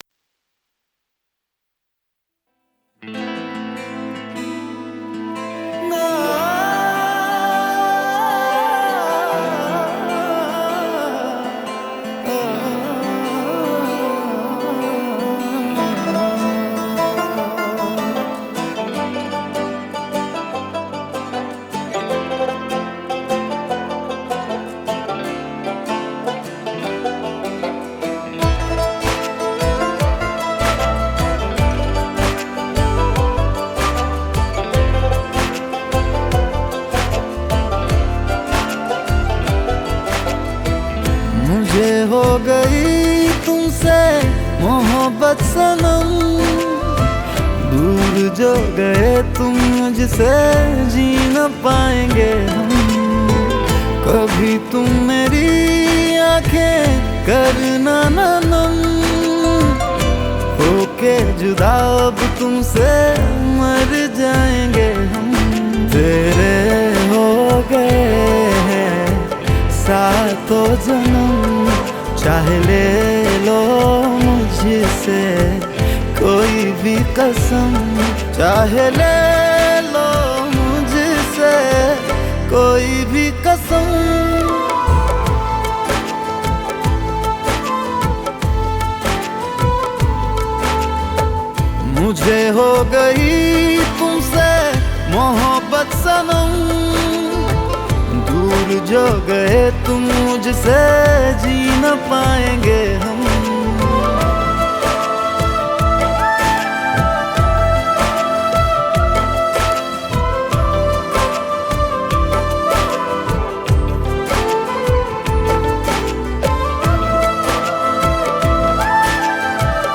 IndiPop Music Album